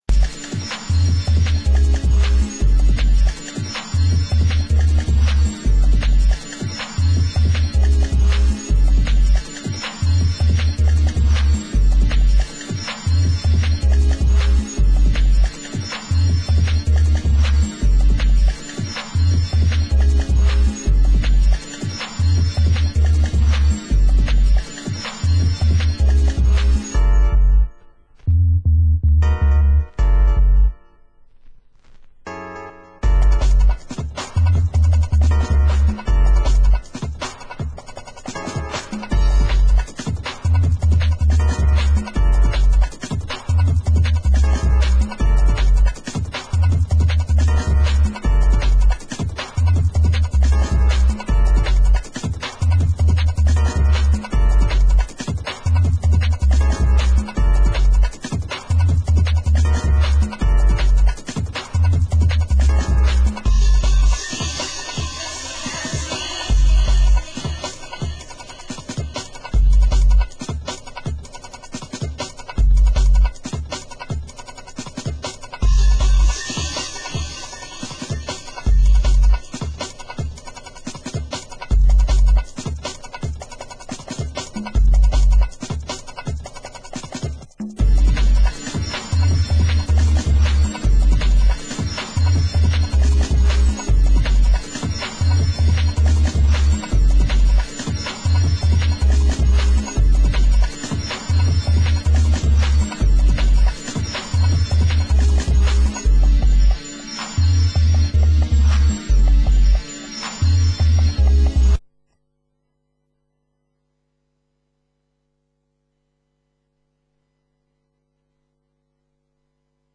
Format: Vinyl 12 Inch
Genre: Drum & Bass